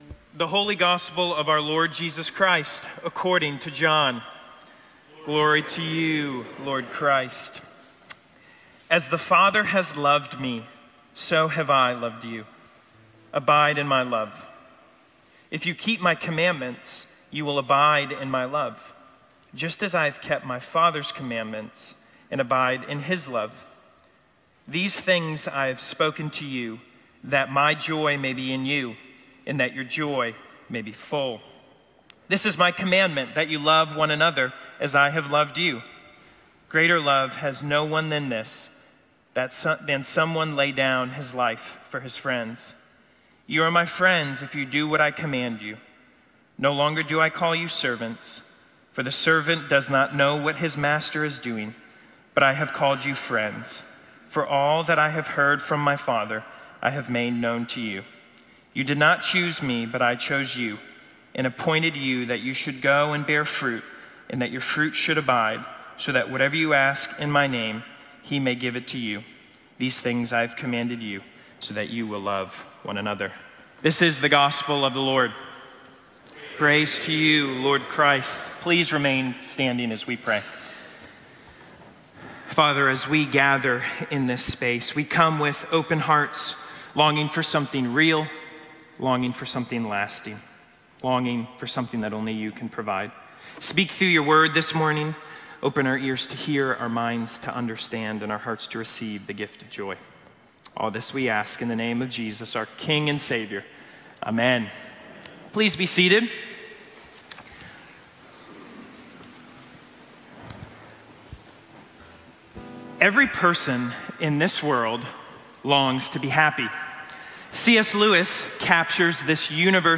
Sermons - Holy Trinity Anglican Church